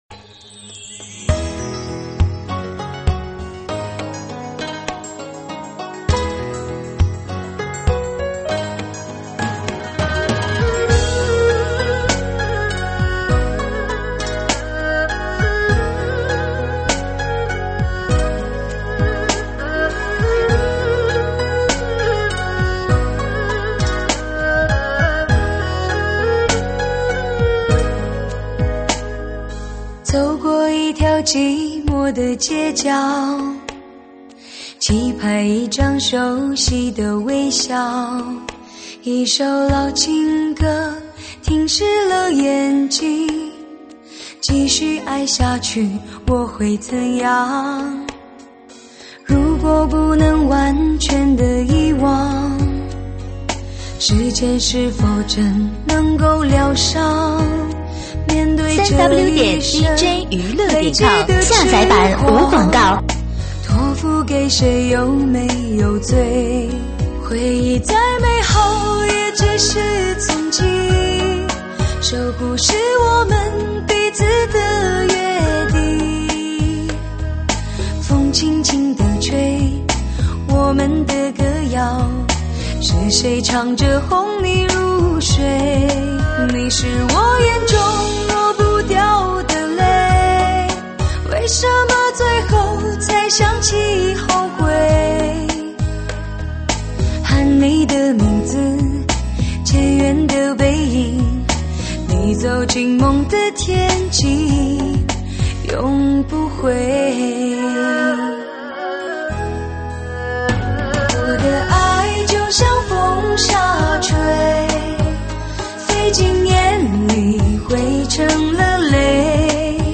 (现场串烧)